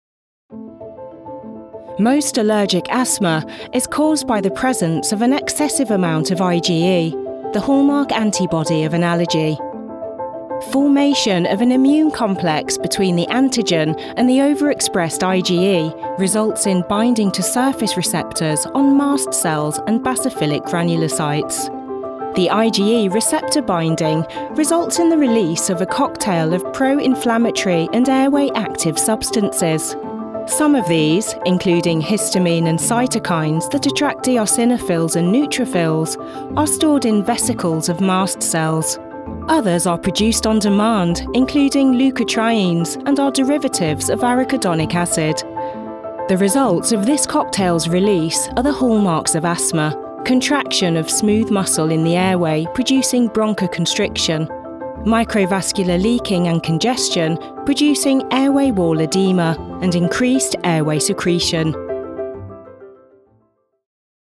Narration médicale
Clair comme du cristal, chaleureux et polyvalent. Une voix britannique naturellement douce – un son neutre avec une douce touche des Midlands ou du Nord si nécessaire.
Home studio : Cabine vocale spécialement conçue, interface Audient ID14, Neumann U87ai et Neumann TLM 102. Source Connect Standard.